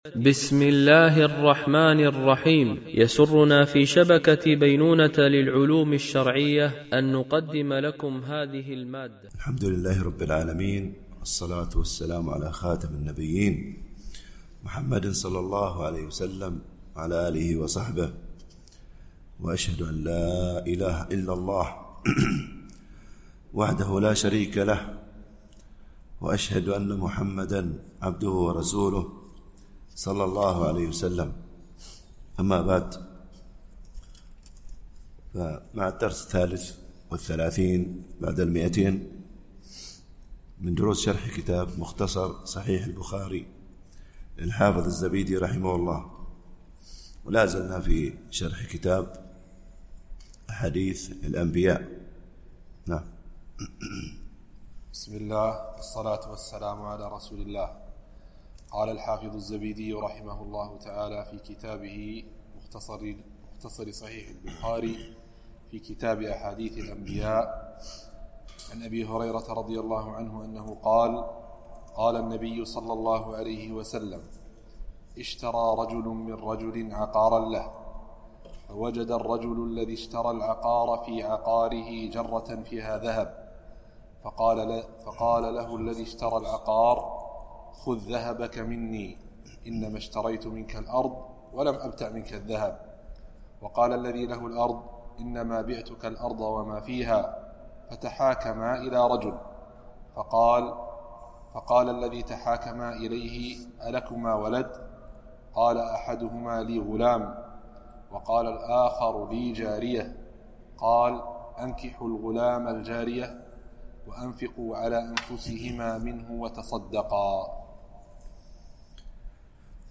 MP3 Mono 44kHz 64Kbps (VBR)